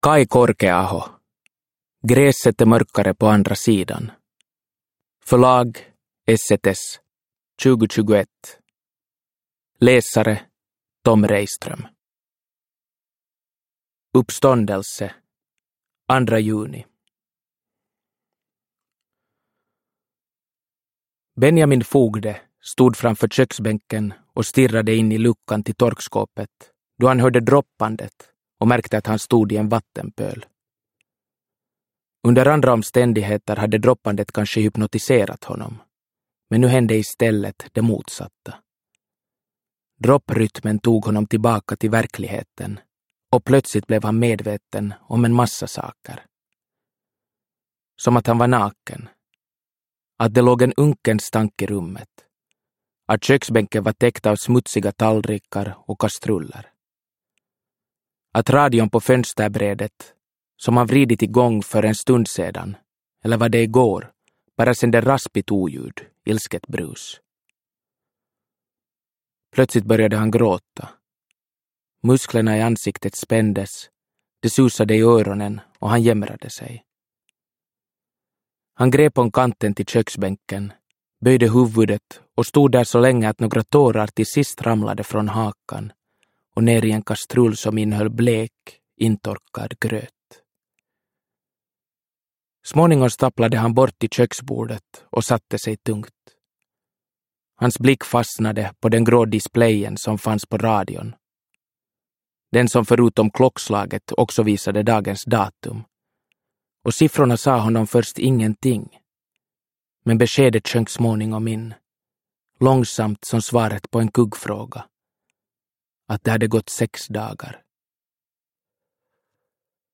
Gräset är mörkare på andra sidan – Ljudbok – Laddas ner